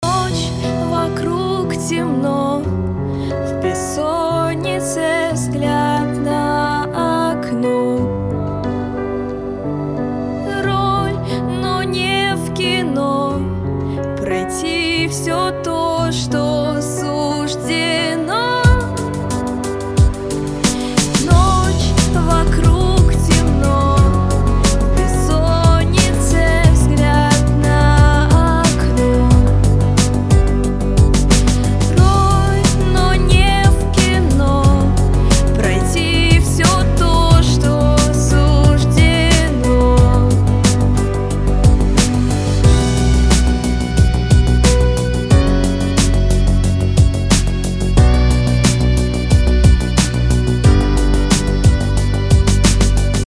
• Качество: 320, Stereo
русский рэп
мелодичные
спокойные
романтичные
красивый женский голос